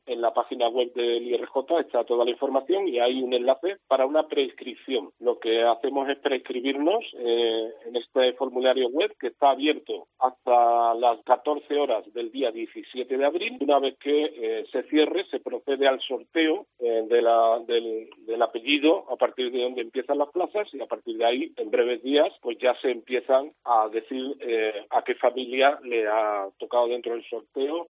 Paco Rivero, director general de Juventud